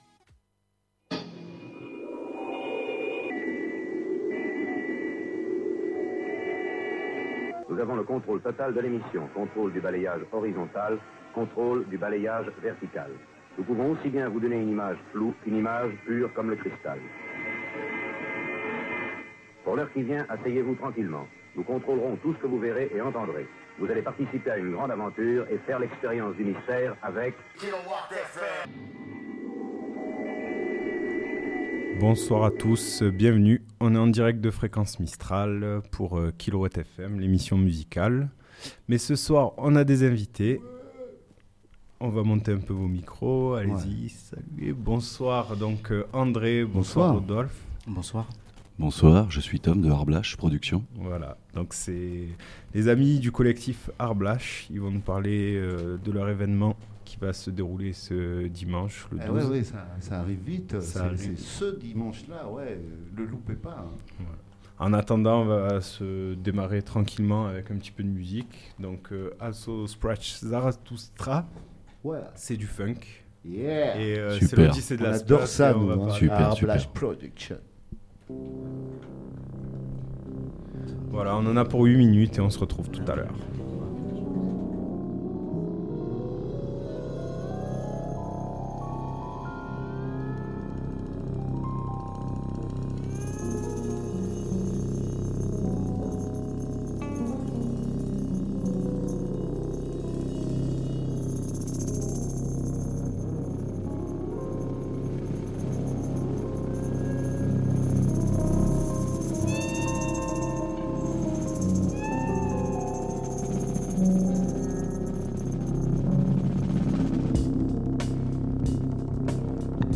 Ce soir en direct de Fréquence Mistral Digne on reçoit Art Blaache pour nous parler de l'évènement qui a lieu dimanche à La Javie.